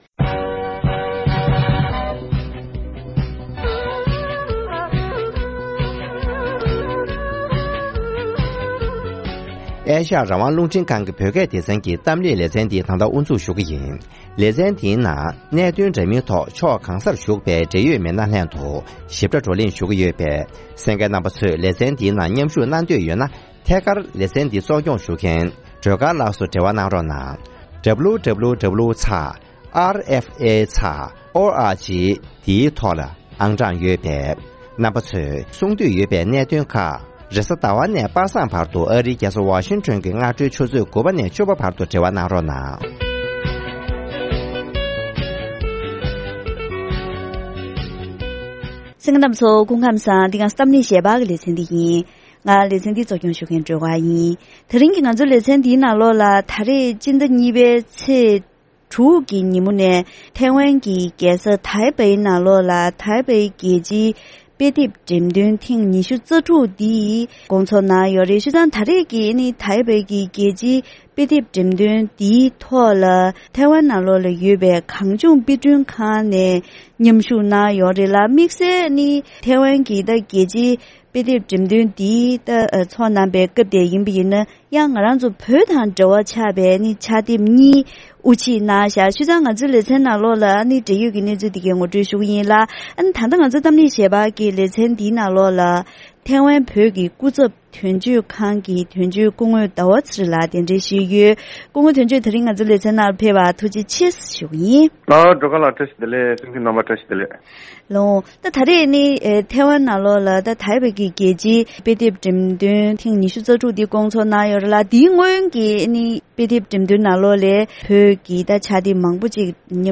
༄༅། །ཐེངས་འདིའི་གཏམ་གླེང་ཞལ་པར་གྱི་ལེ་ཚན་ནང་དུ།